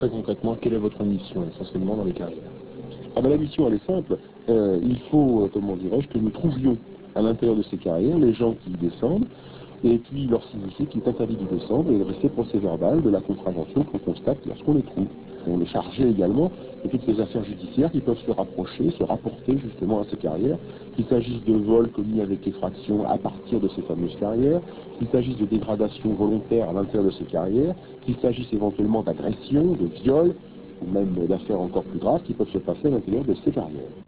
Extracts from France Inter radio show "Les Visiteurs du Noir"